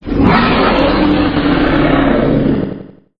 c_trex00_atk2.wav